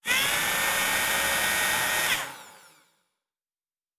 pgs/Assets/Audio/Sci-Fi Sounds/Mechanical/Servo Big 9_1.wav at 7452e70b8c5ad2f7daae623e1a952eb18c9caab4
Servo Big 9_1.wav